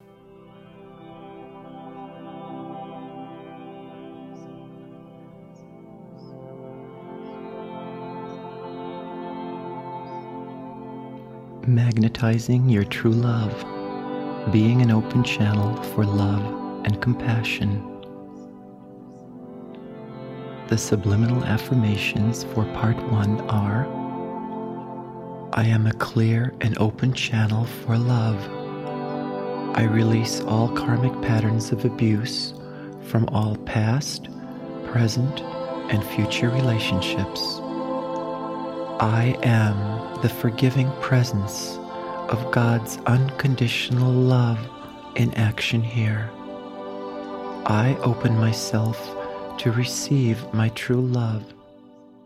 A dynamic digital download from a 2 CD set featuring 2 powerful meditations & visualization exercises designed to open and heal your heart, and to prepare you for your true love.